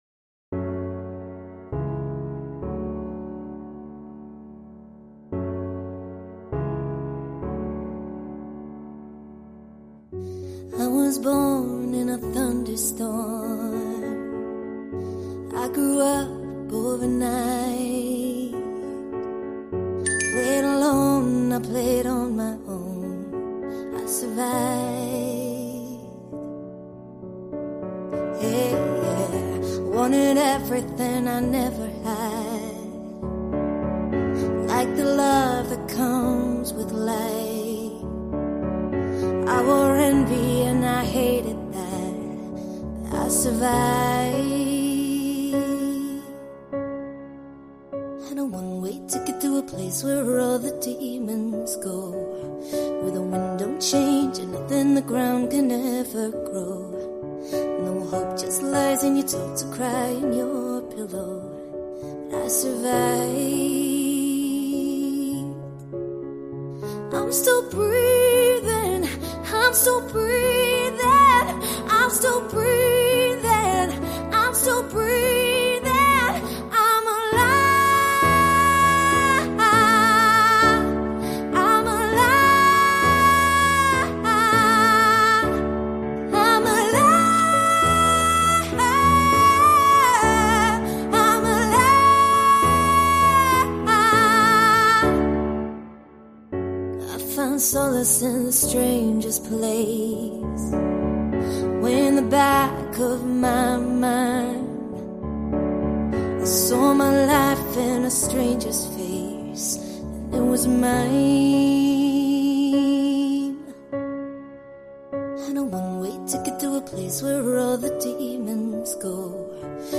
DarkPop